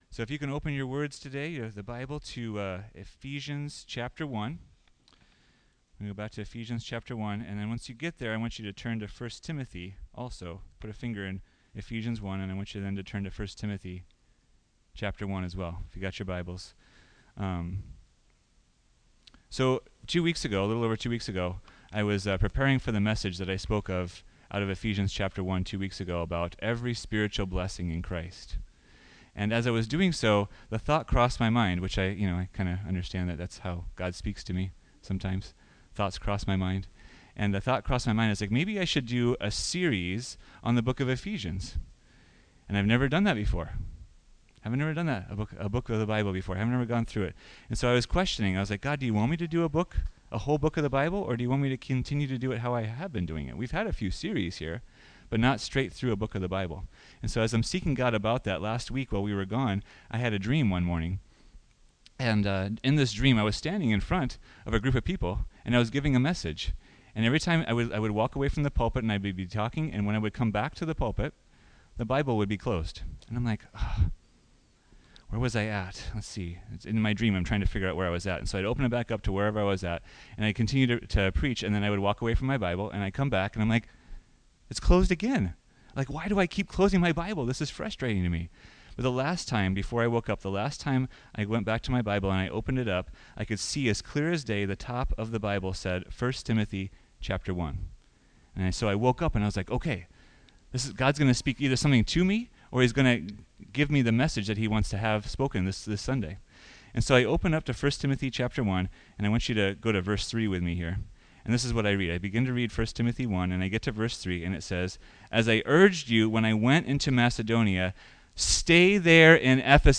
Listen in as the 2nd message in our new series is shared on the Book of Ephesians.